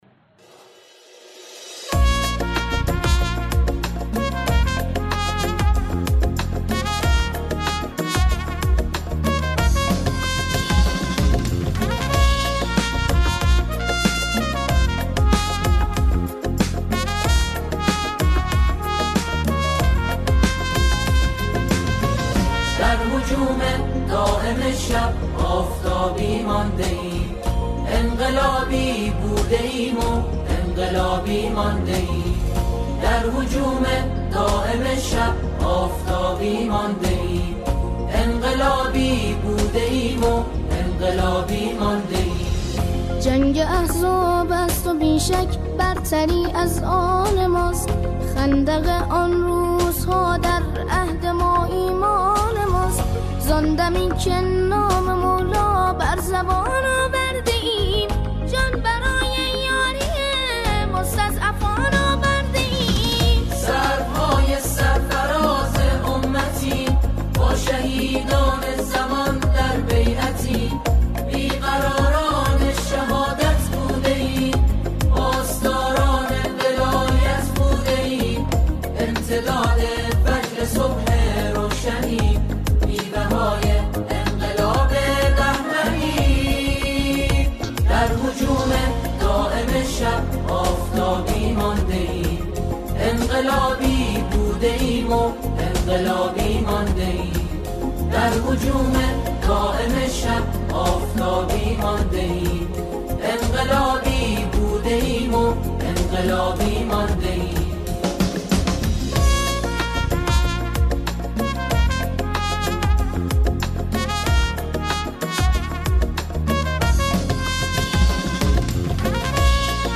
سرودهای انقلابی